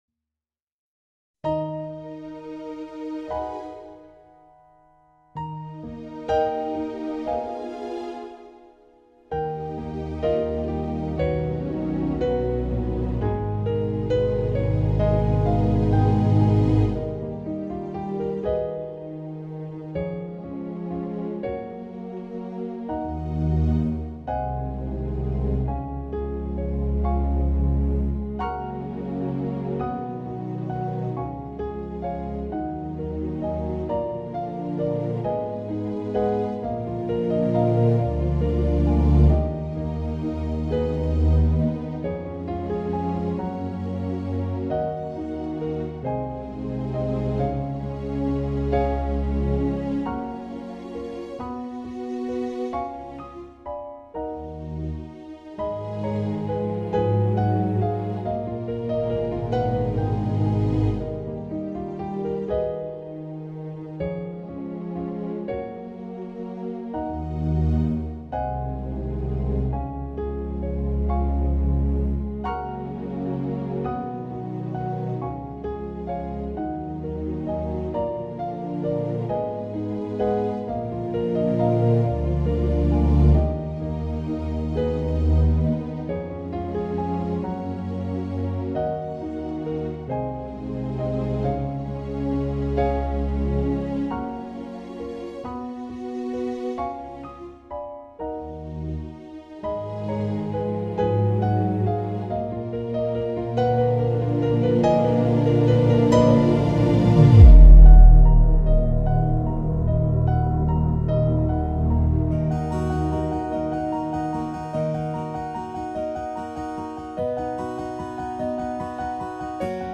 minus the lyrics